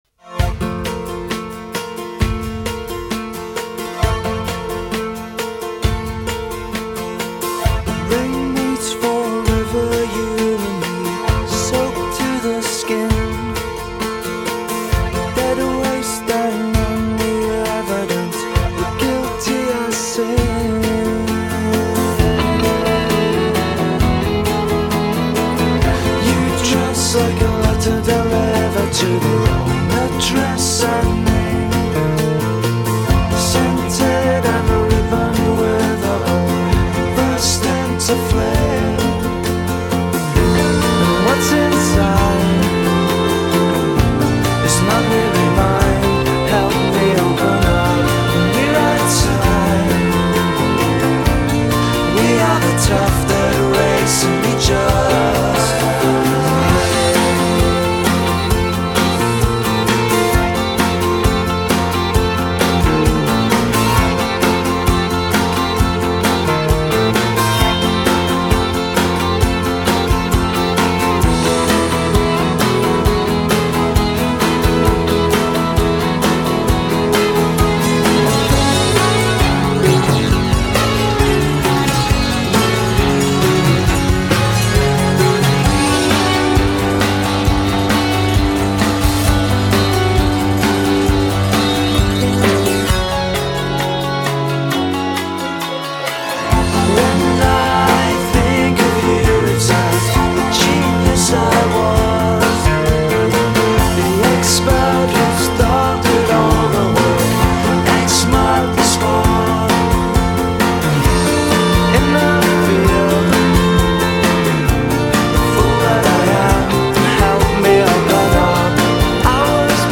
slightly hippy, slightly trippy